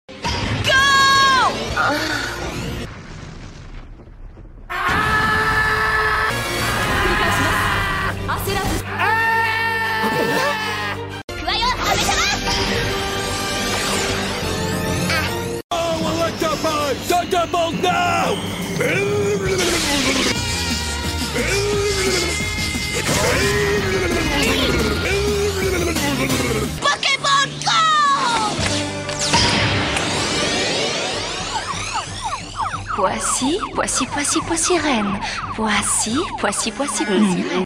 funniest pokemon sounds - MP3 Download
The funniest pokemon sounds sound button is from our meme soundboard library
u3-funniest-pokemon-sounds.mp3